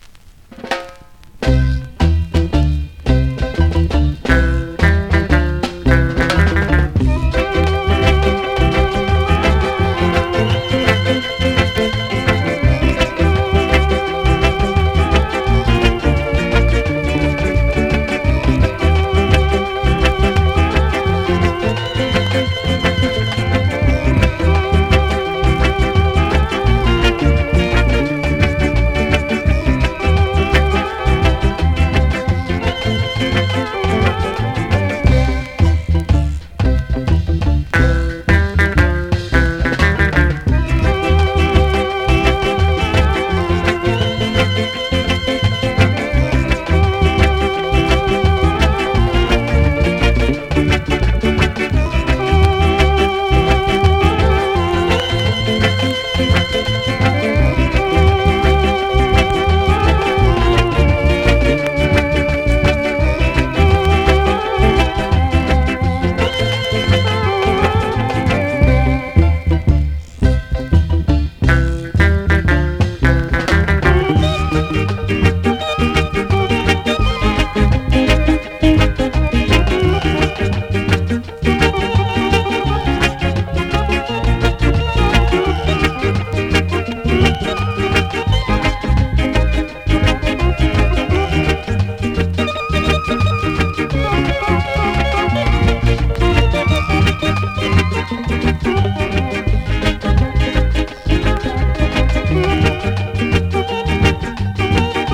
2024!! NEW IN!SKA〜REGGAE
スリキズ、ノイズ比較的少なめで